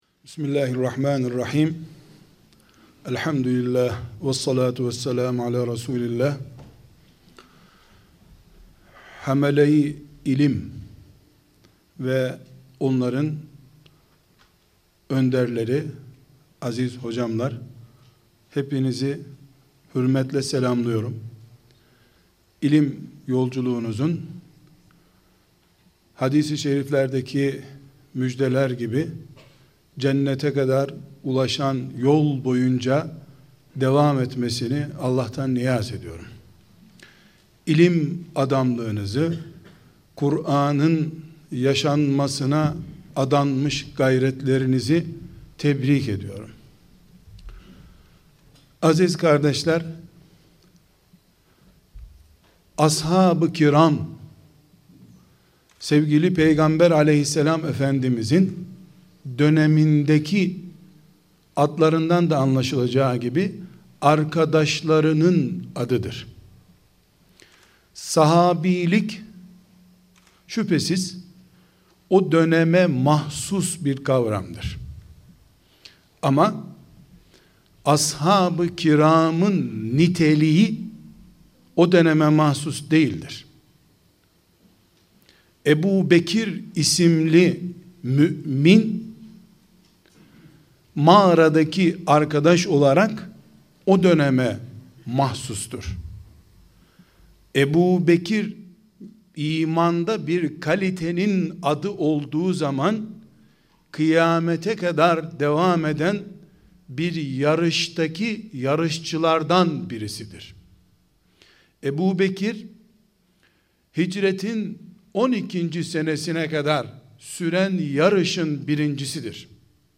erciyes-zamanin-sahabisi-olmak-konferansi.mp3